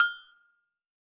PAGOGO HI.wav